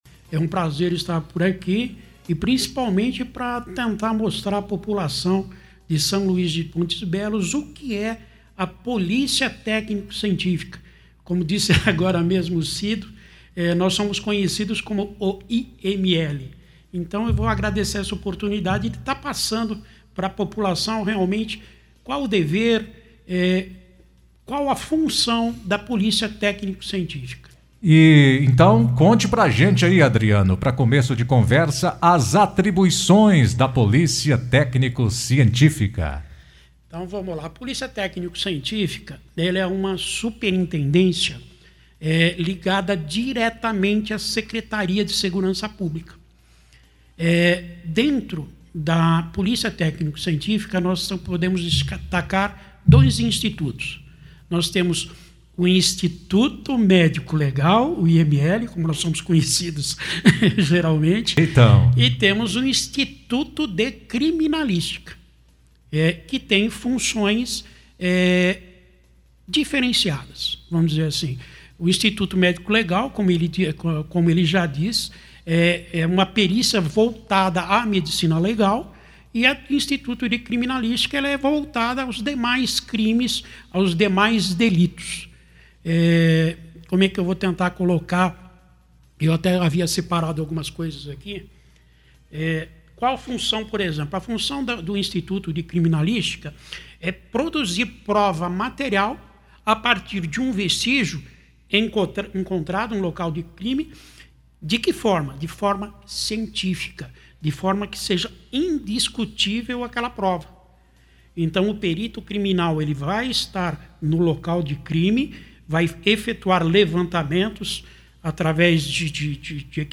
compareceu ao estúdio de uma das emissoras da rede, a Vale da Serra, para esclarecer dúvidas da população acerca do funcionamento dessa polícia.